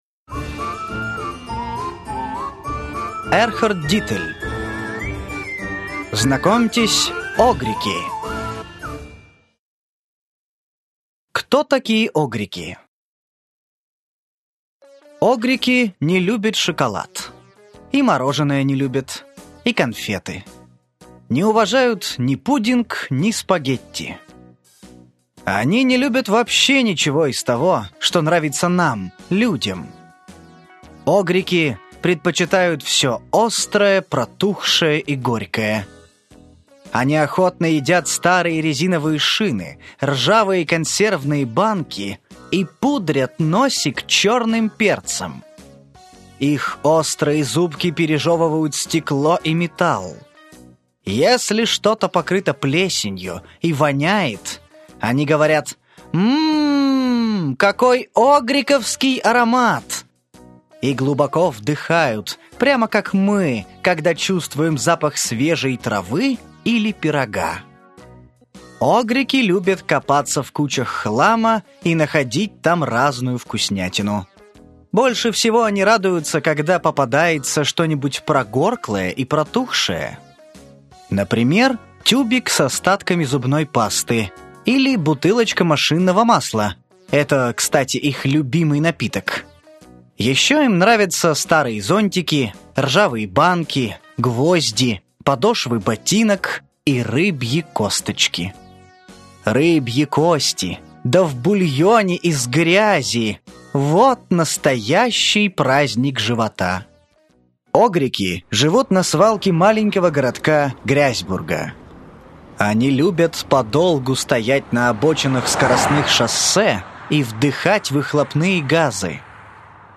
Аудиокнига Знакомьтесь, Огрики! Сборник историй | Библиотека аудиокниг